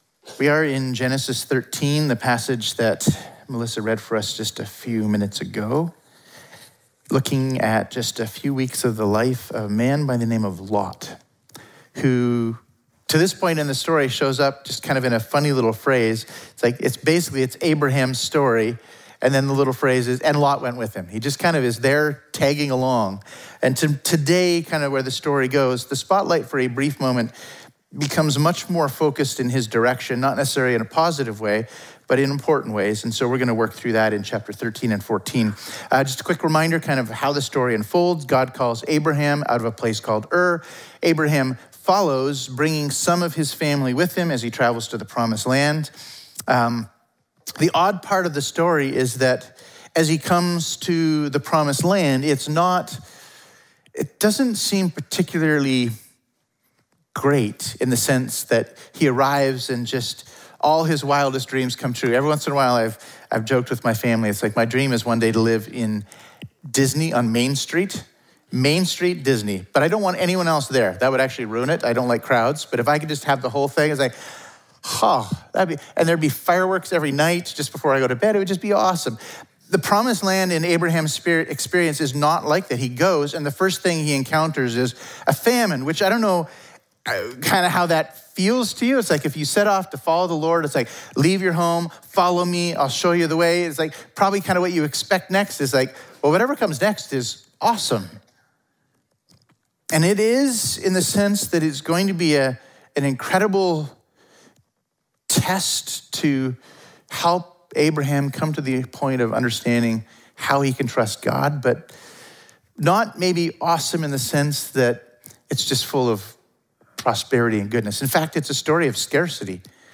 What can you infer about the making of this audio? Our apologies -- due to some technical problems, approximately the last 3 minutes of the sermon are missing.